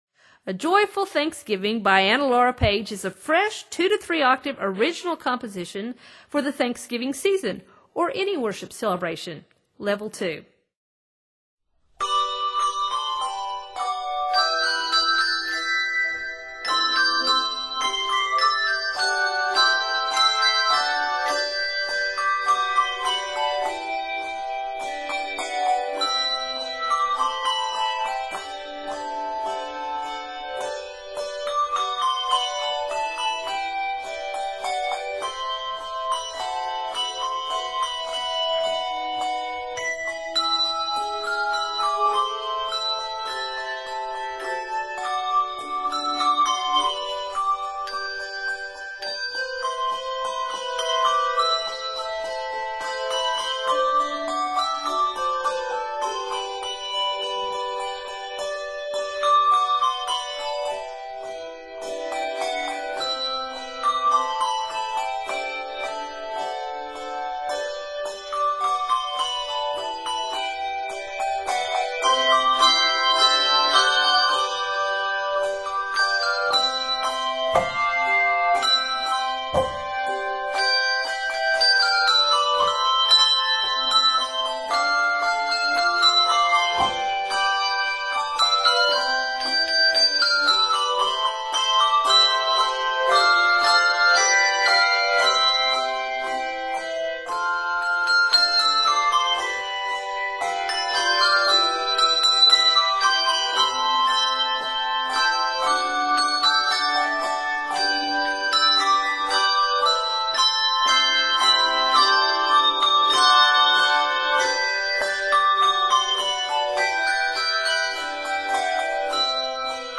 Scored in G Major and C Major